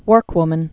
(wûrkwmən)